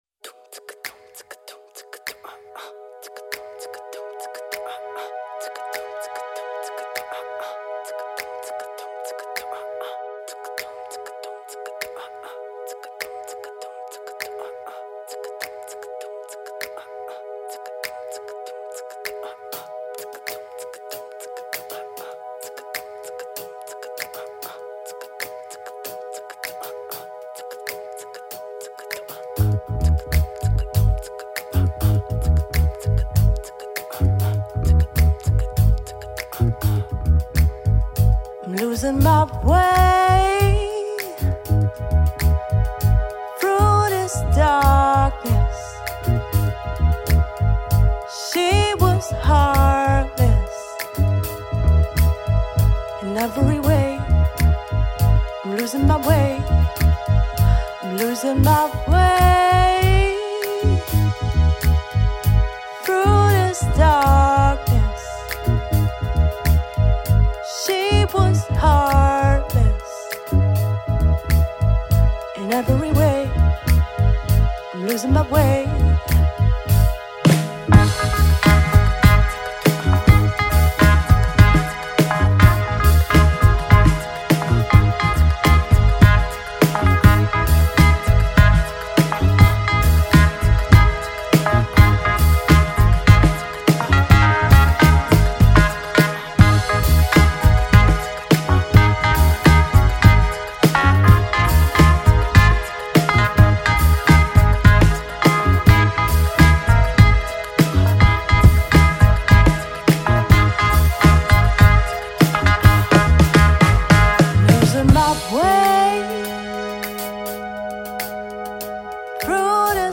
Jazz, Soul und Groove mit spontaner Spielfreude
Gesang
Keyboards
Saxophon
Schlagzeug
Bass